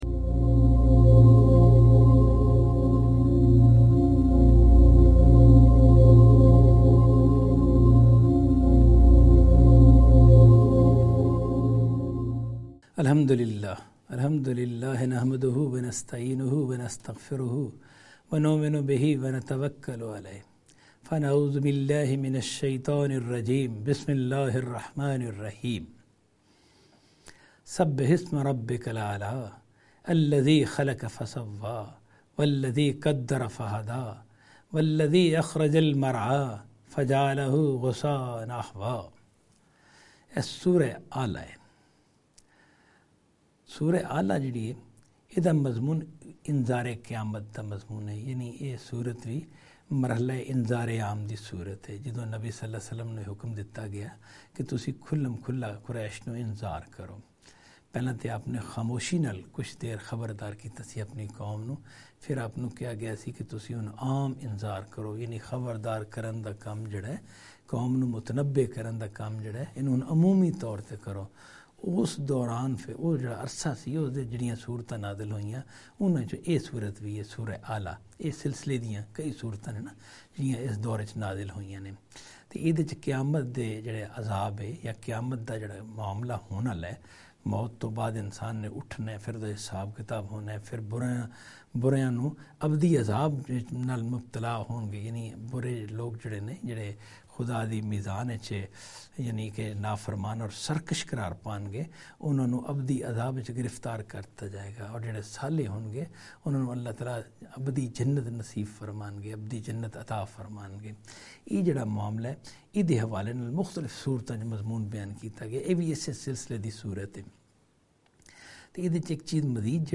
Qur'anic Surah Lecture series in Punjabi